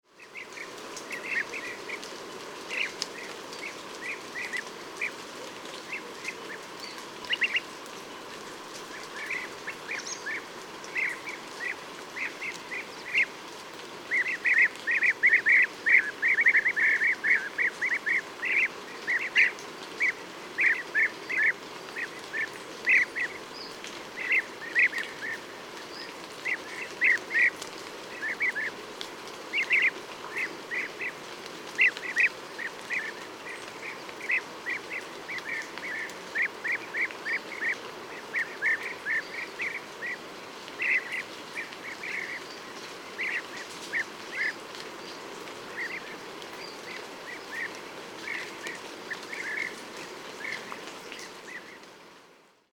PFR15798, 190917, Caucasian Chiffchaff Phylloscopus lorenzii, social call, Batumi, Georgia